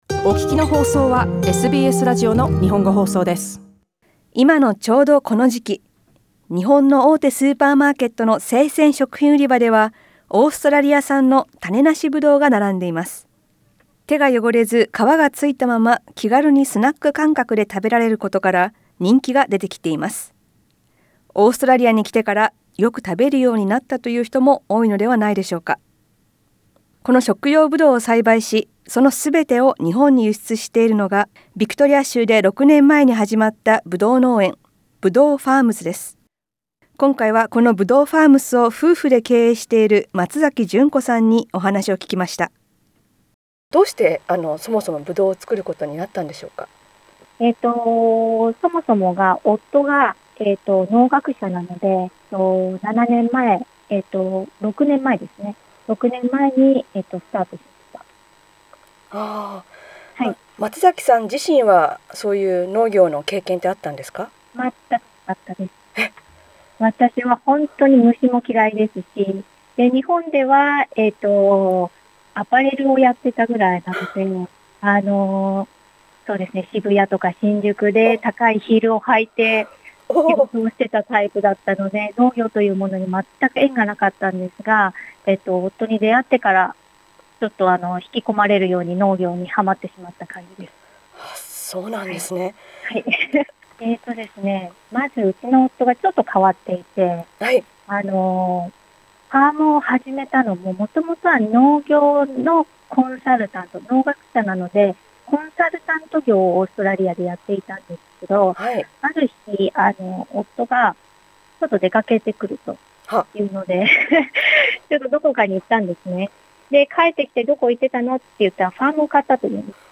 インタビューでは、農場を始めたきっかけや、ブドウの魅力、ファームでの生活などを聞きました。